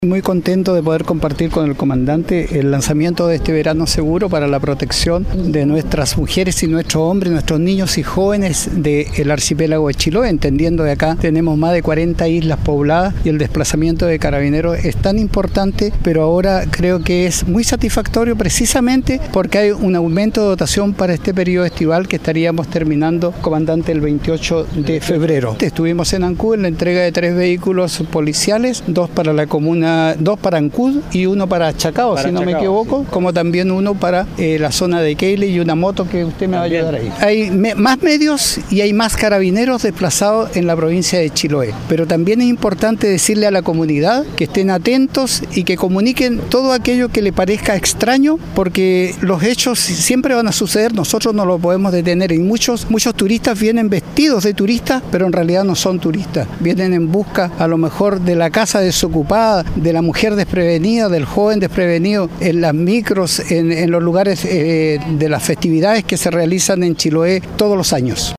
En un acto simbólico realizado en la Plaza de Castro y acompañado por representantes de Carabineros, la autoridad se refirió al foco al que apuntan con el programa, invitando a la ciudadanía a prevenir la ocurrencia de delitos en sus sectores: